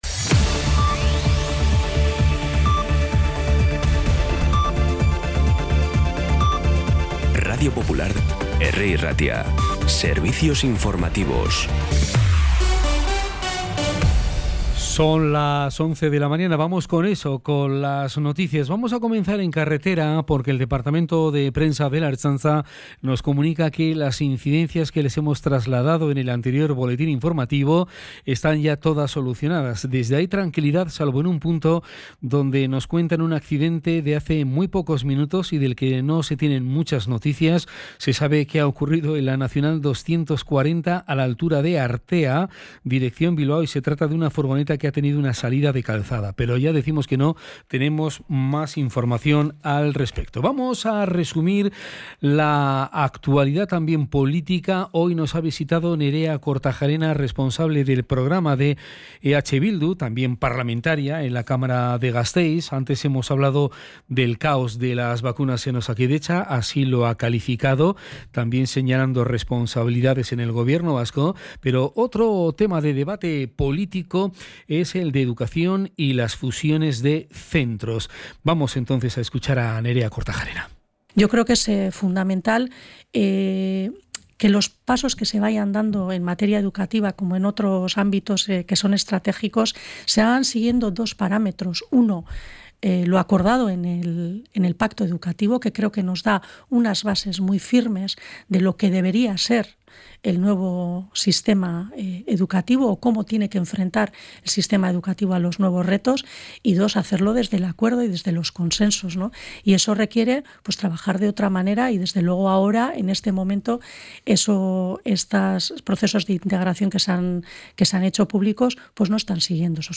Las noticias de Bilbao y Bizkaia del 2 de febrero a las 11
Los titulares actualizados con las voces del día. Bilbao, Bizkaia, comarcas, política, sociedad, cultura, sucesos, información de servicio público.